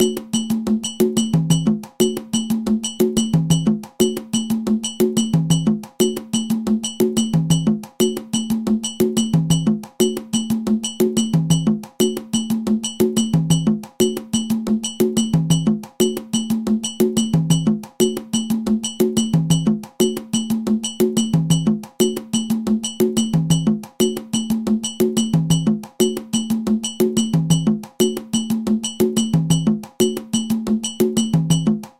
La musique abakuás possède une signature rythmique en 6/8.
La variante de la Havane se joue à un rythme très rapide alors que celle de Matanzas est plus lente.
Ensemble de la rythmique de l'Abakuá
abakua_matanzas.mp3